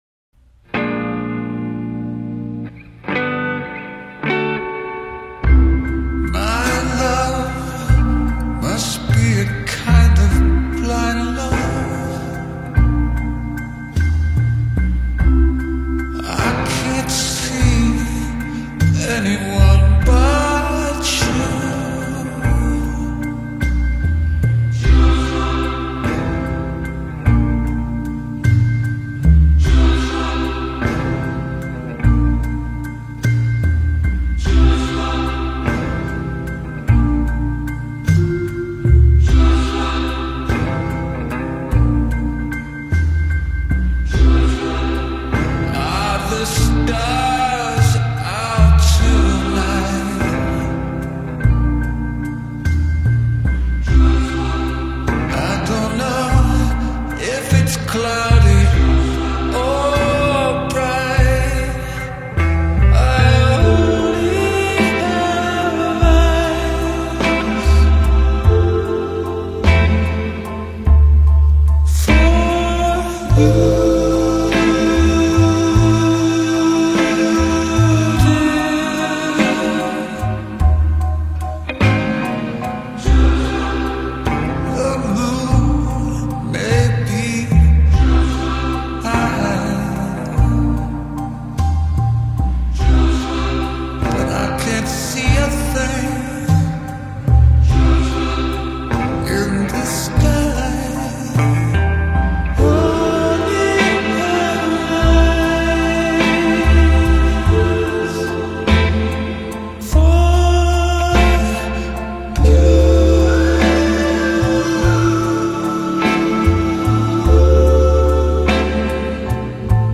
It is dreamy, slightly surreal, and unmistakably his.